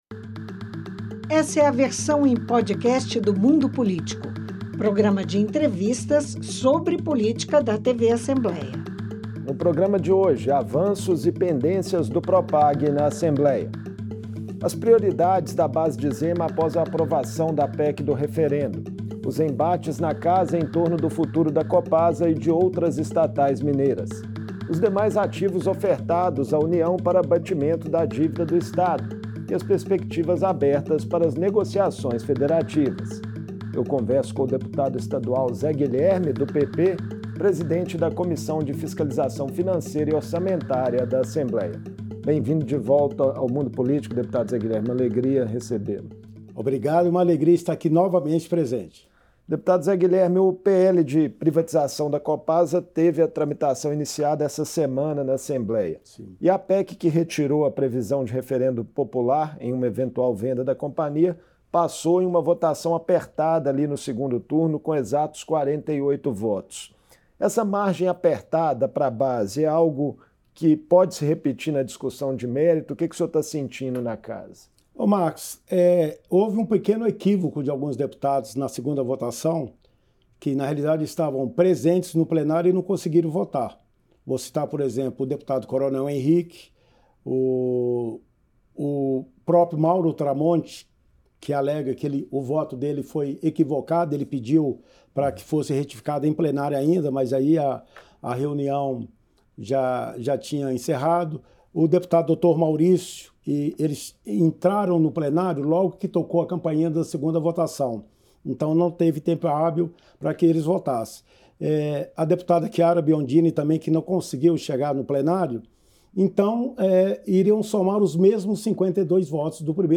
Com a PEC do referendo já aprovada e o PL da privatização da Copasa tramitando na Assembleia, a base do governo Zema trabalha para passar a matéria e a oposição promete obstruir nas Comissões e no Plenário. Em entrevista